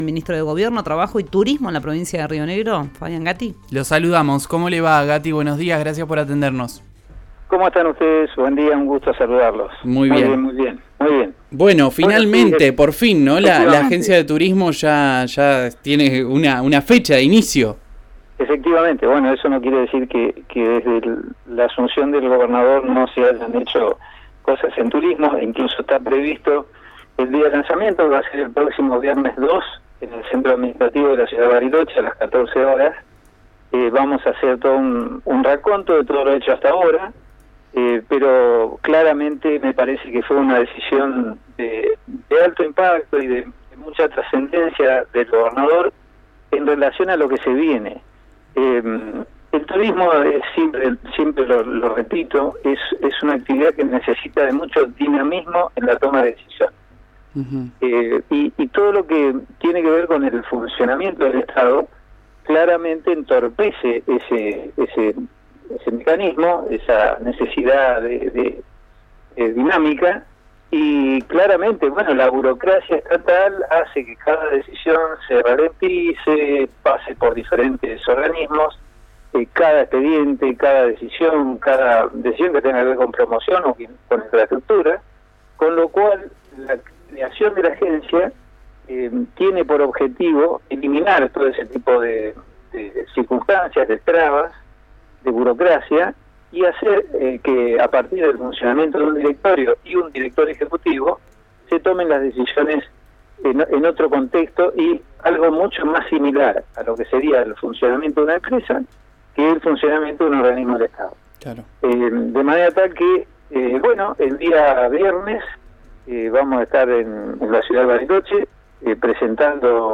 Escuchá a Fabián Gatti, ministro de Gobierno, Trabajo y Turismo de la provincia de Río Negro en RÍO NEGRO RADIO: